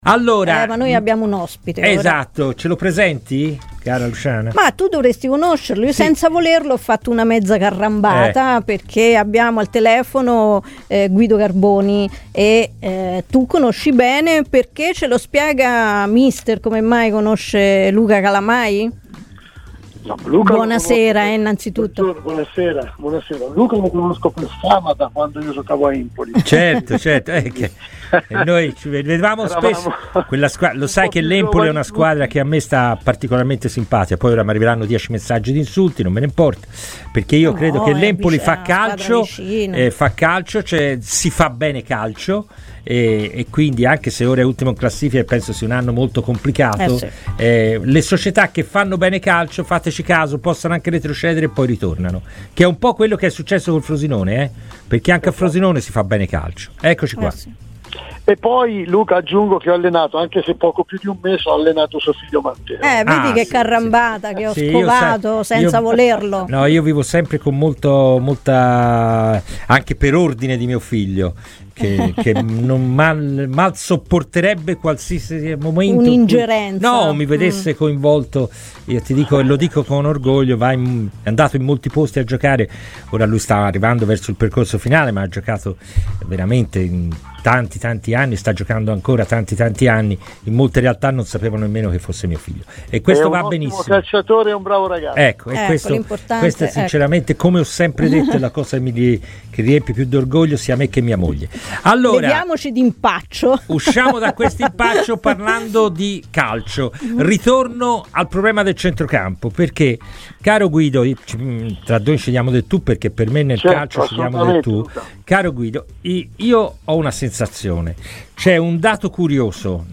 QUI SOTTO IL PODCAST CON L'INTERVENTO INTEGRALE